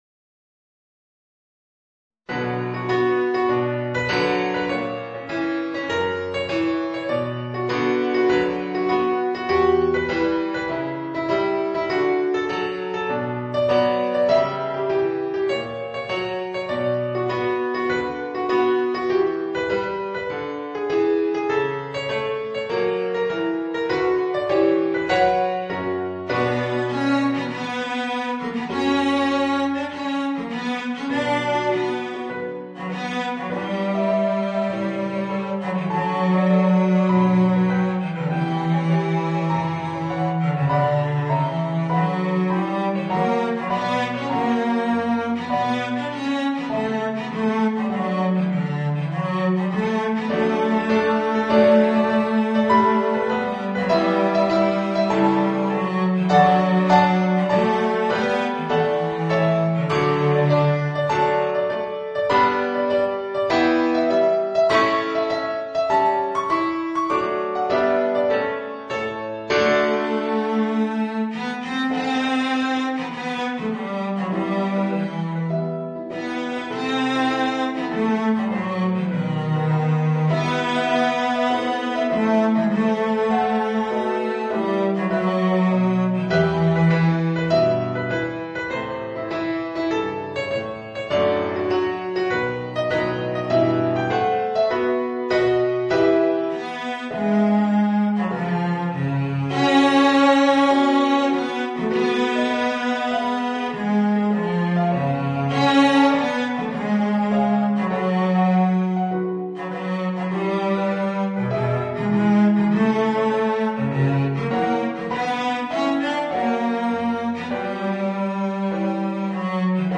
Voicing: Violoncello and Piano